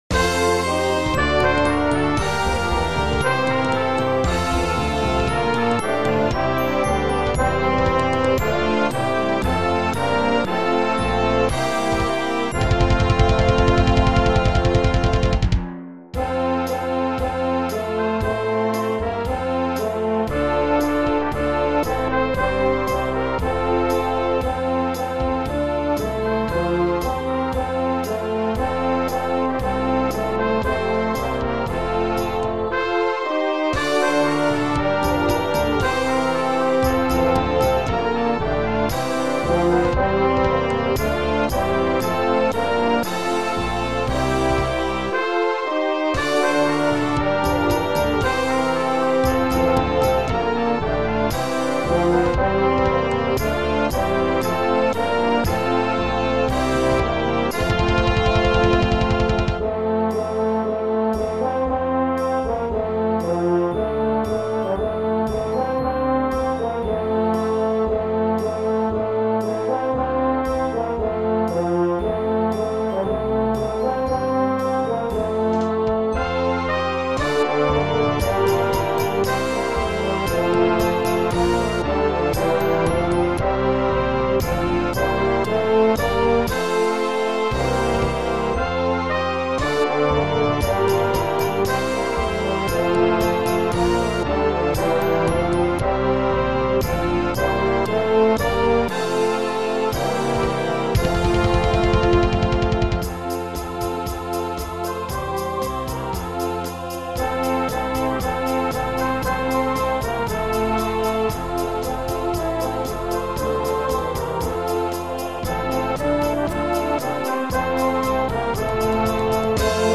50B1 Brass Band $20.00 **
(computer generated sound sample)